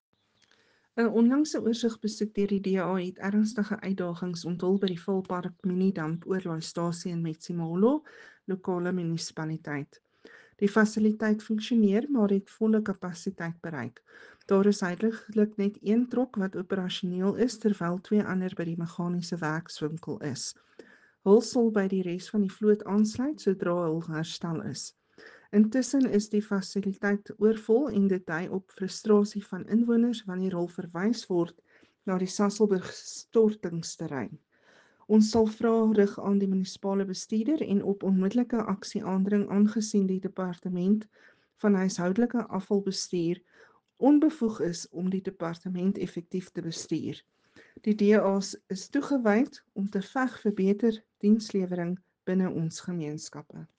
Afrikaans soundbites by Cllr Ruanda Meyer and Sesotho soundbite by Jafta Mokoena MPL.